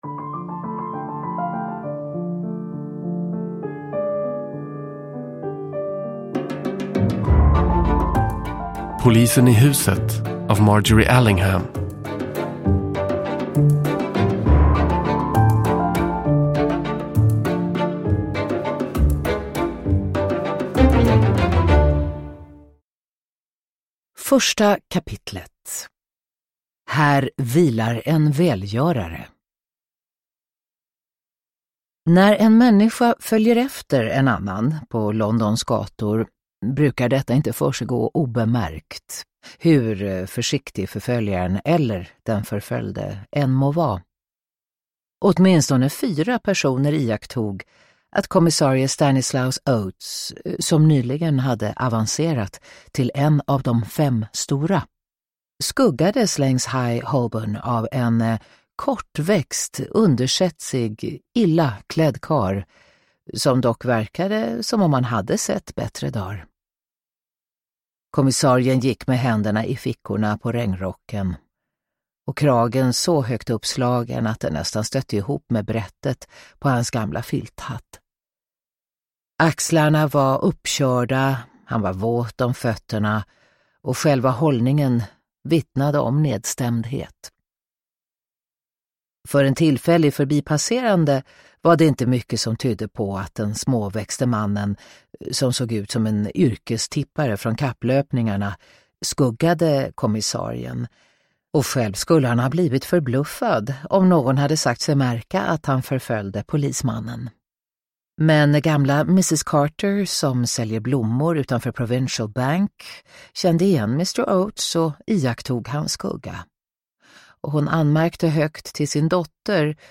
Polisen i huset – Ljudbok – Laddas ner
Uppläsare: Katarina Ewerlöf